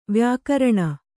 ♪ vyākaraṇa